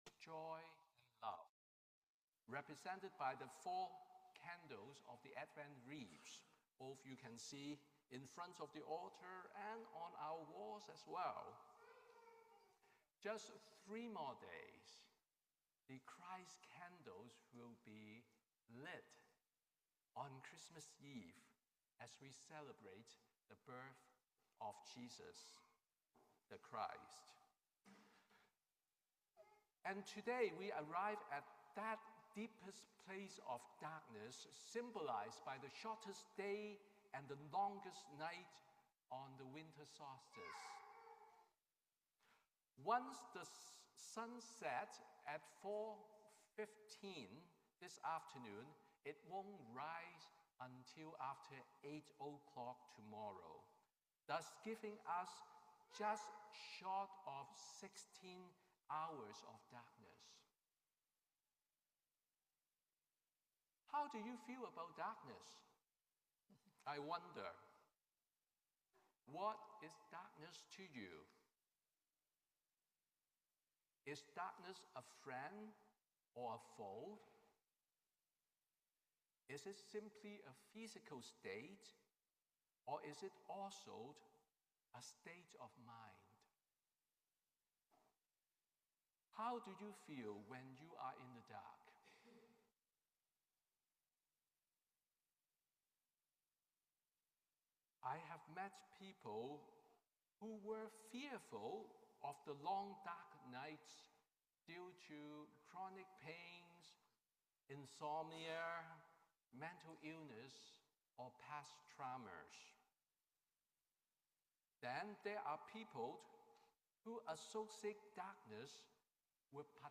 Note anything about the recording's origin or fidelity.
Sermon on the Fourth Sunday of Advent